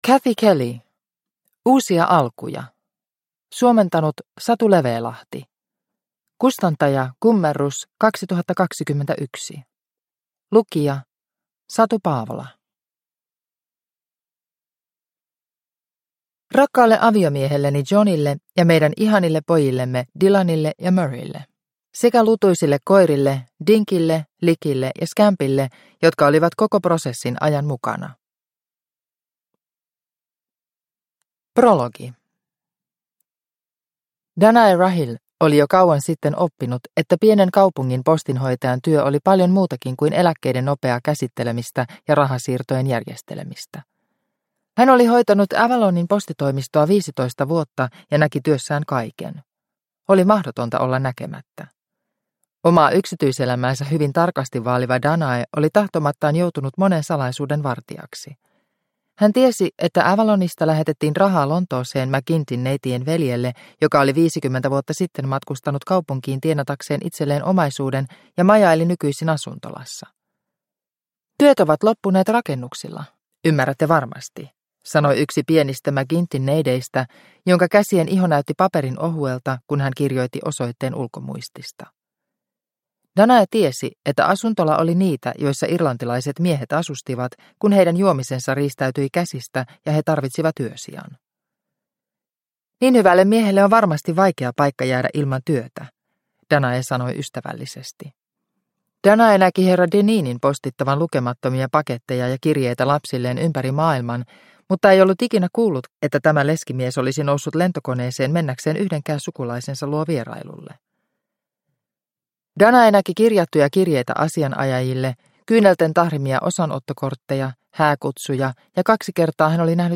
Uusia alkuja – Ljudbok – Laddas ner